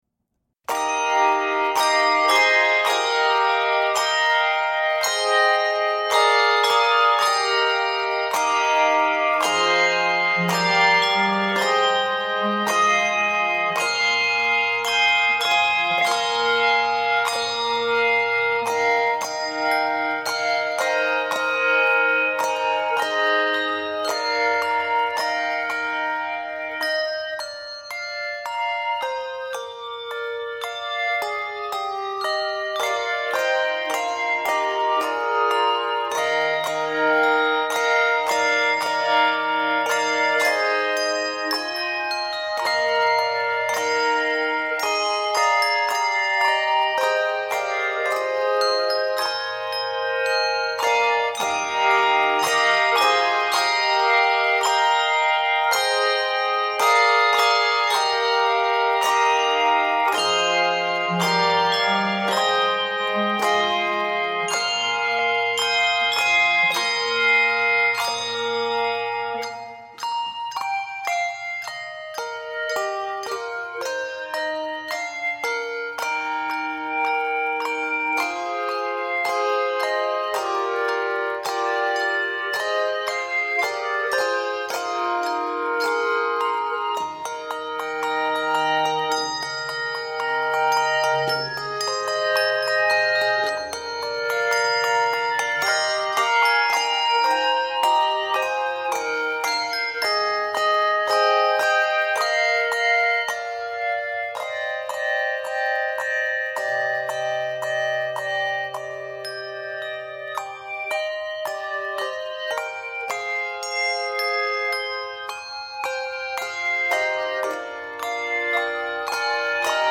stately arrangement